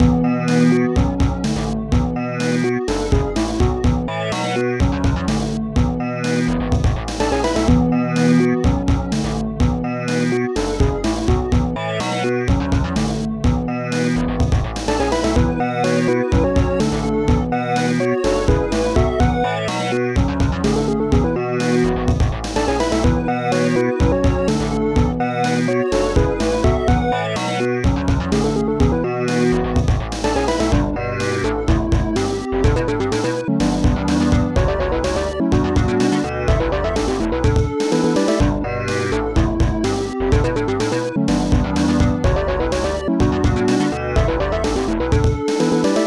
Protracker and family
st-02:perc-bongo
st-01:bassdrum3
st-01:strings1